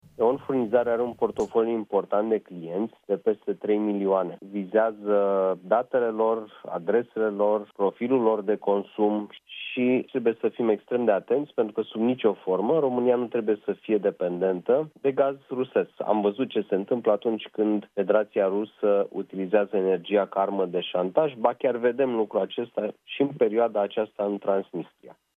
Urmează o analiză aprofundată și nu există un termen pentru un răspuns în acest sens – a mai spus ministrul Energiei, la Europa FM.